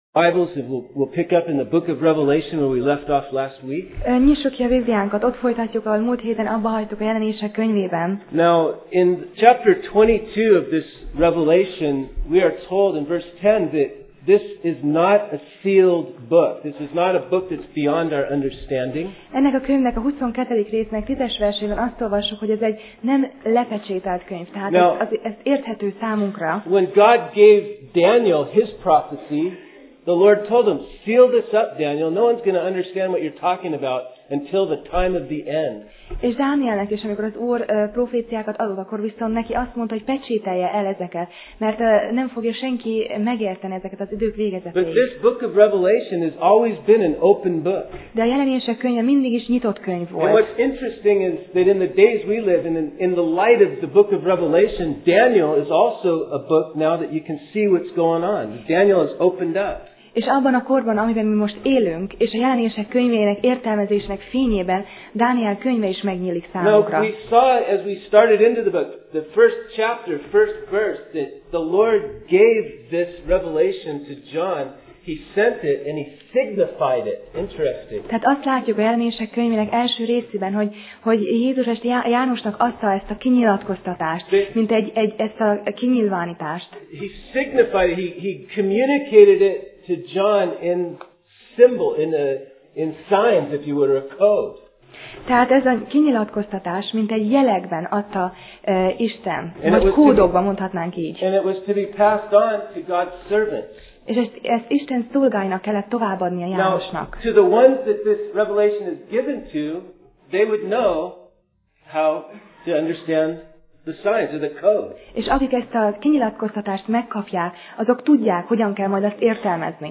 Passage: Jelenések (Revelation) 7:9-17 Alkalom: Vasárnap Reggel